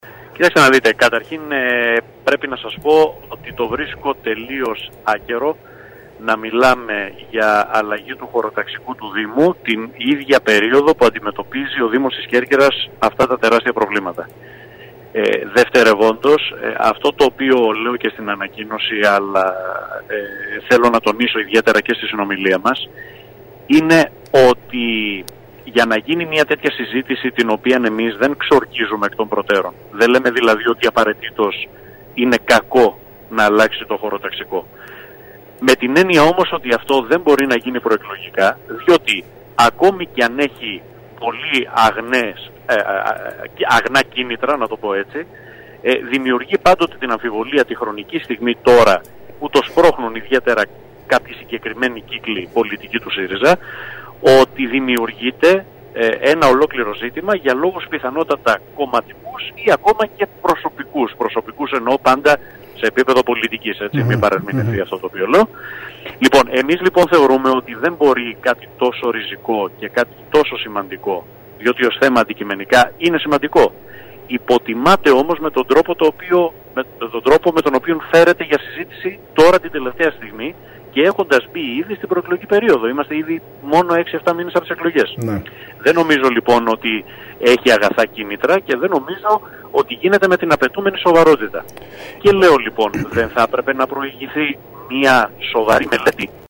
μιλώντας στην ΕΡΤ Κέρκυρας